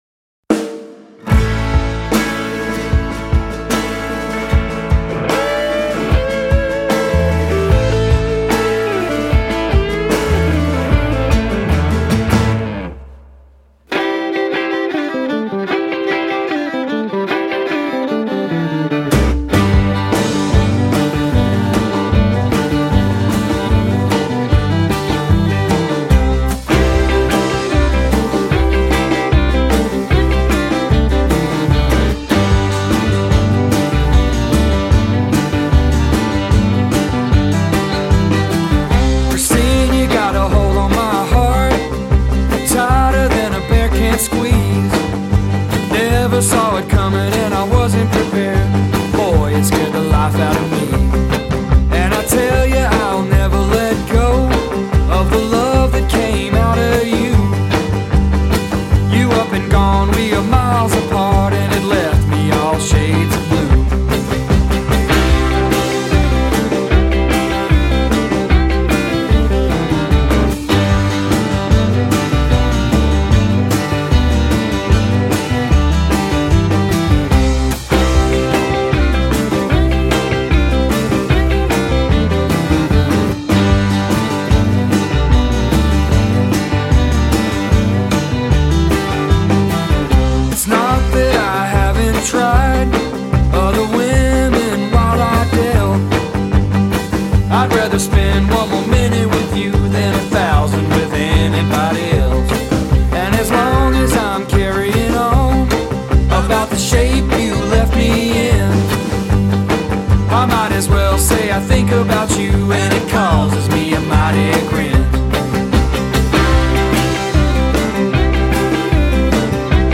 Jam To Bluegrass-Folk